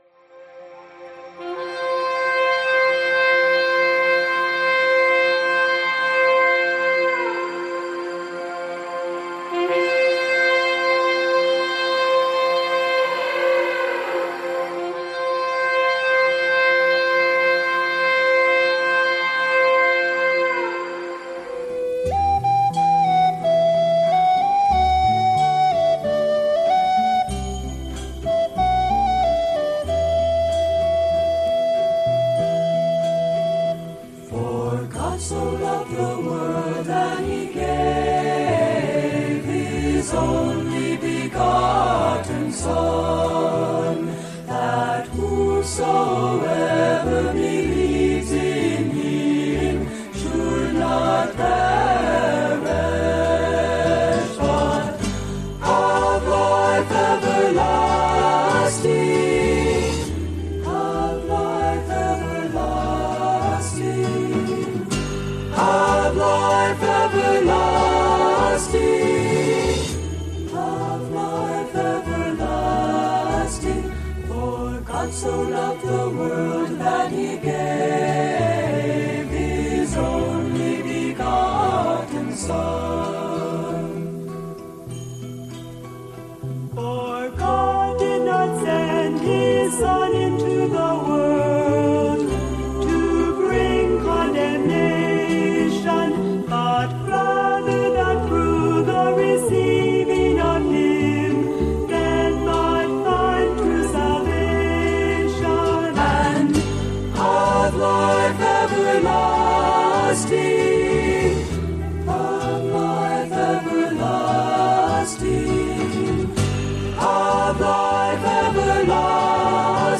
The prayer time starts at 9:00 PM EST. The prayer is scheduled for one hour, but I will continue the show if needed.